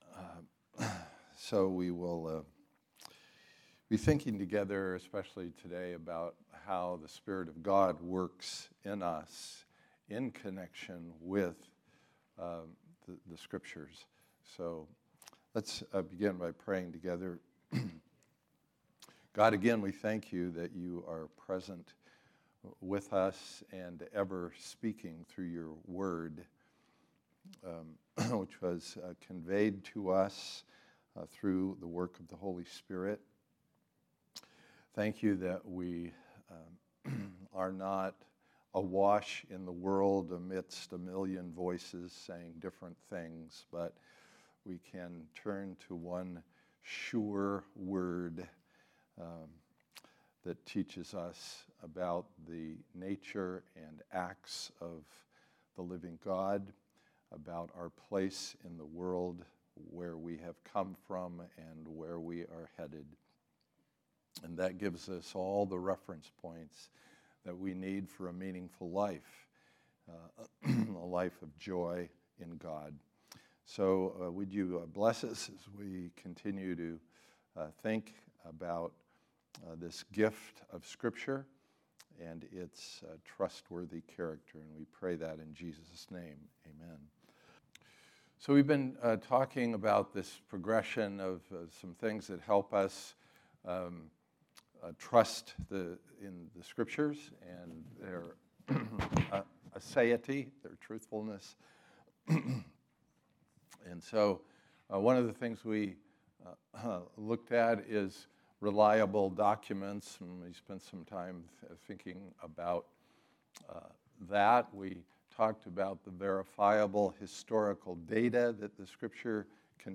2023 Series: Trustworthy Type: Sunday School